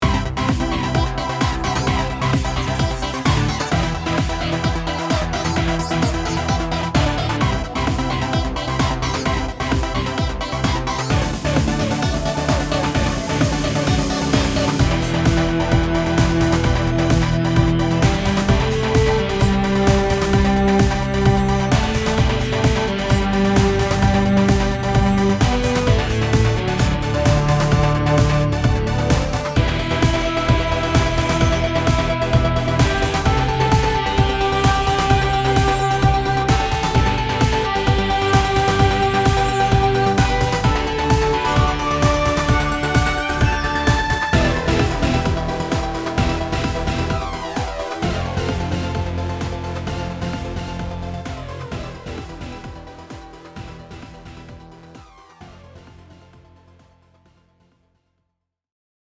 ポップスからEDM系のダンス・ミュージックまでカバーできる
金属的なデジタル音が多いので、ゲーム系などのBGMにも向いているように思います。
こんな感じの曲も14トラックくらいで作ることができます。
HALion-Demo.mp3